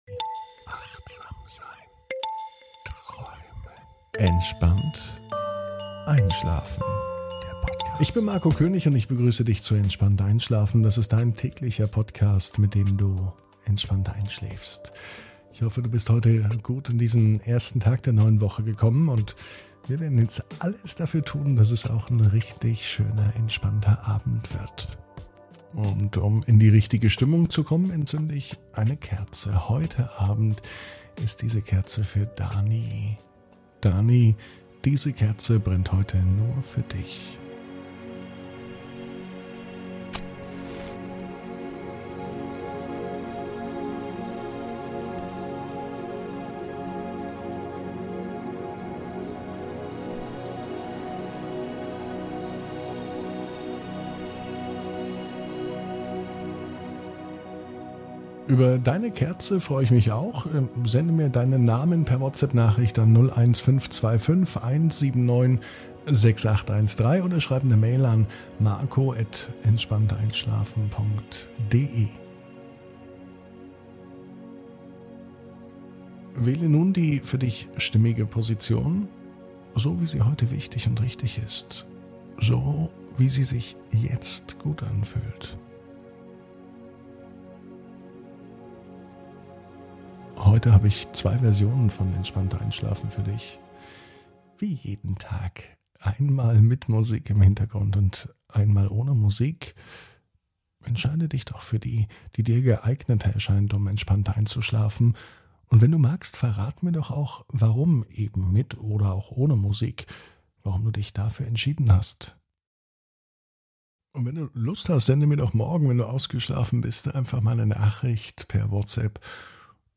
(ohne Musik) Entspannt einschlafen am Montag, 15.08.22 ~ Entspannt einschlafen - Meditation & Achtsamkeit für die Nacht Podcast